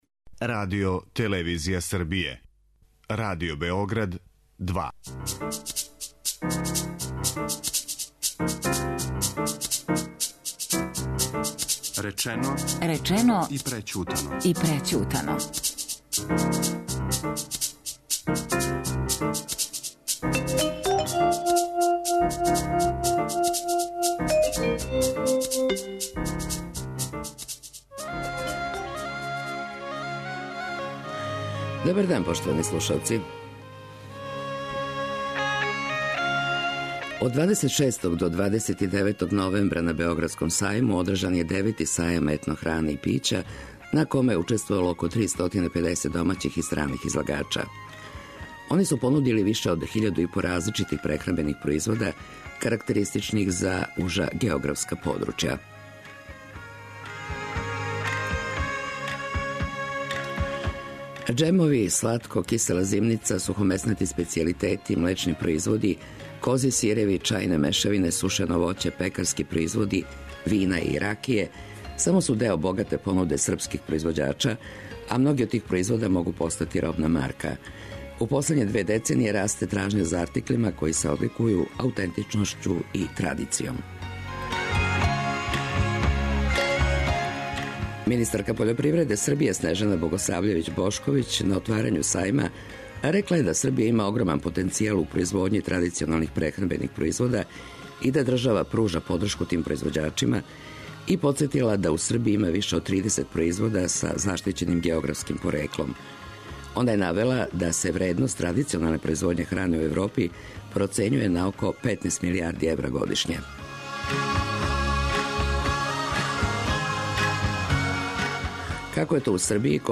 Од 26. до 29. новембра на Београдском сајму одржан је 9. Сајам етно хране и пића...